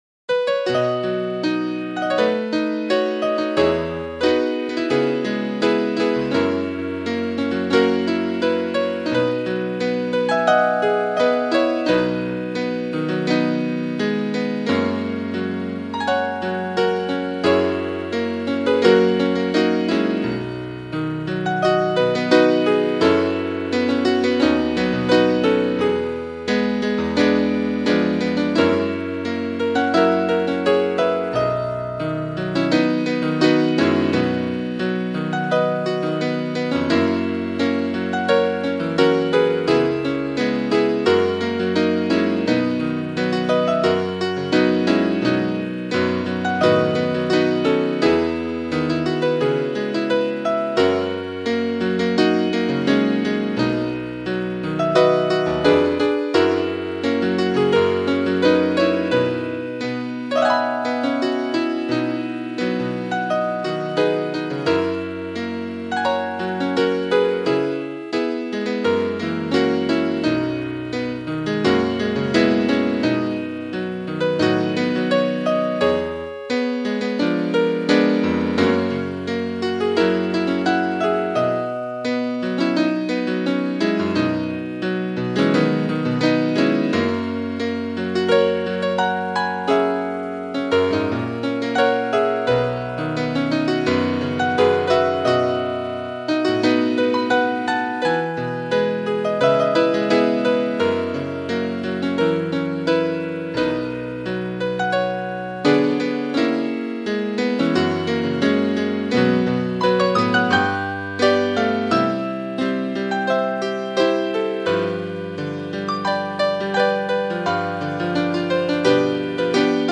Cor mixt